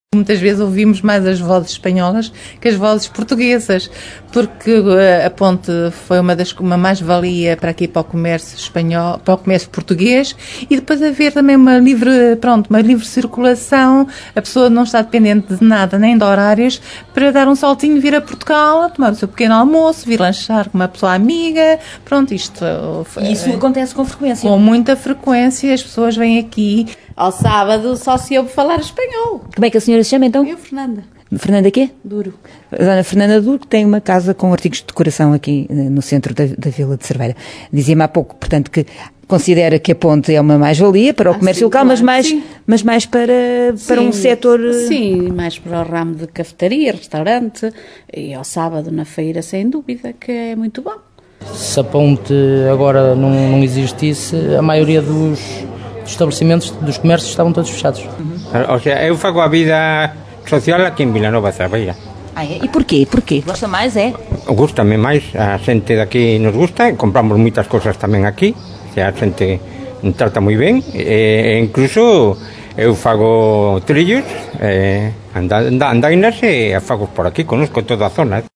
Vivência comum, essa já é uma realidade desde que a ponte foi construída. Foi o que confirmaram à Rádio Caminha os habitantes dos dois lados da fronteira.